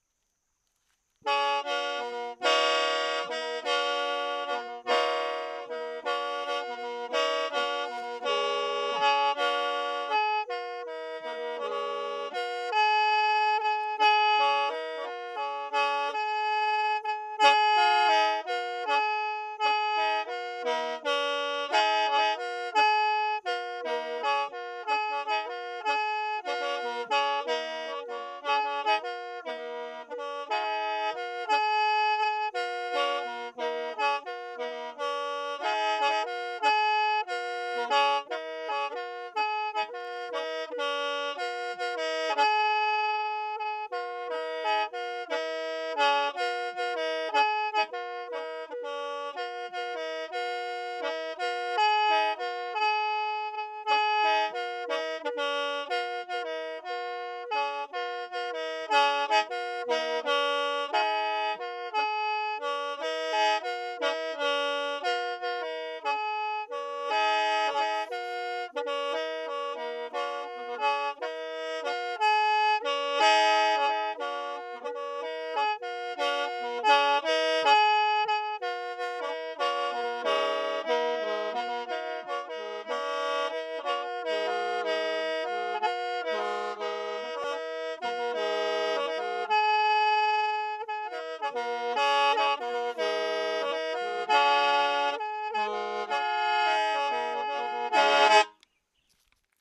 wedding music played on reed pipes (qen) 1.5MB
Track 05 Hmong wedding music on khaen.mp3